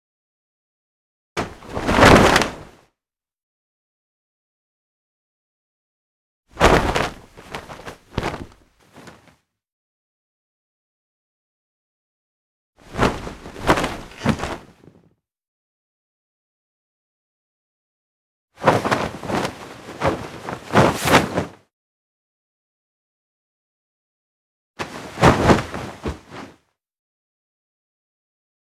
Cloth Blanket Sound
household
Cloth Blanket